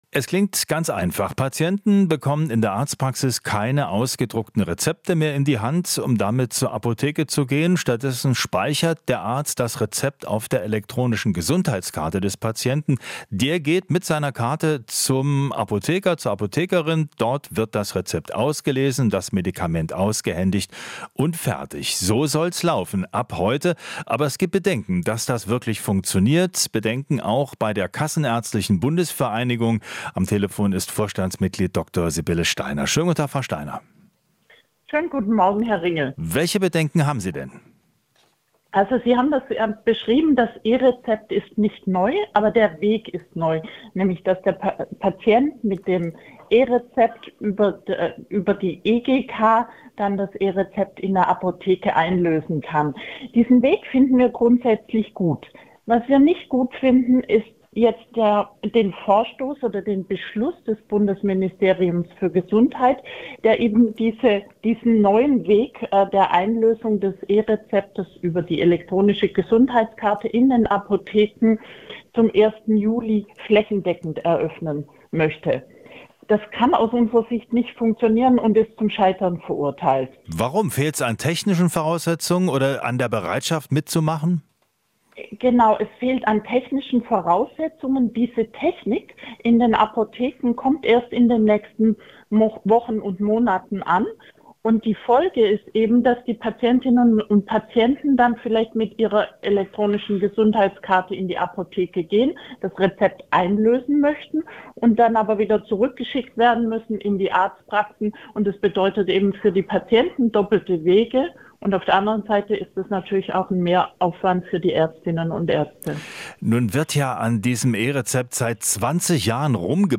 Interview - Kassenärztliche Bundesvereinigung: E-Rezepte zum Scheitern verurteilt